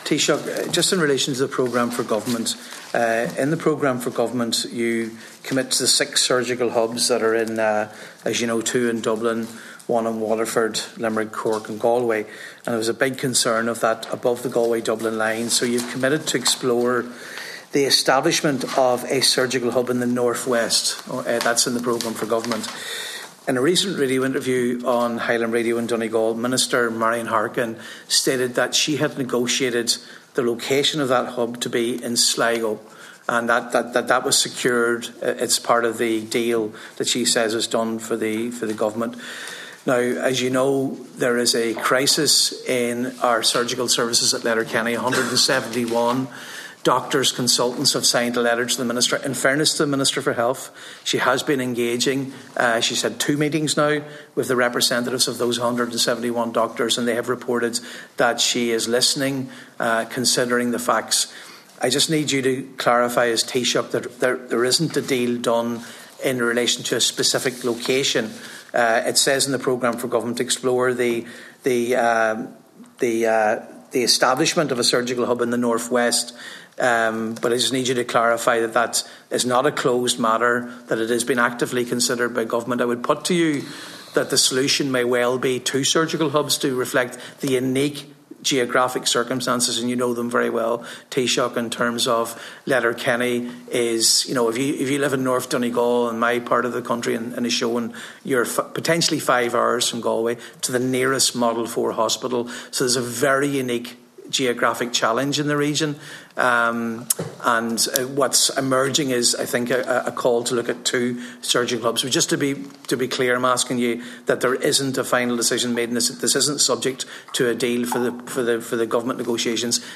In response to Donegal Deputy Padraig MacLochlainn, Michael Martin confirmed that a decision has yet to be reached: